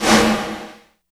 44 VERB SN-R.wav